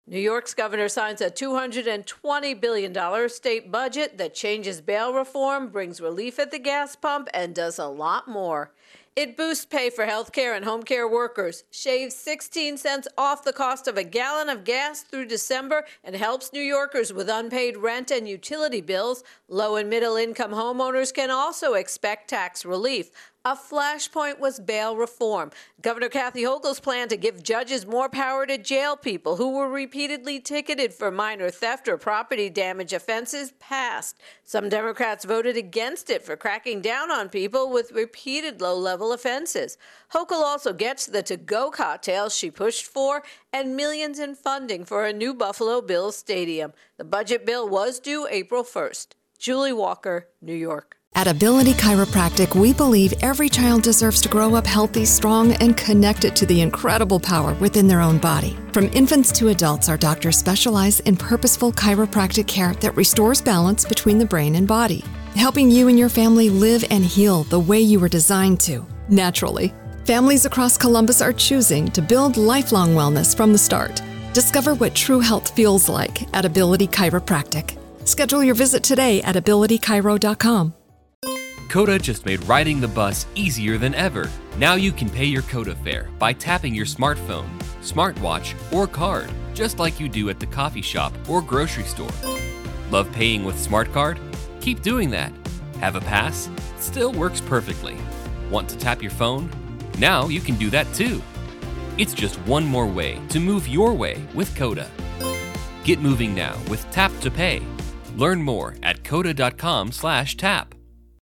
Late Budget New York intro and voicer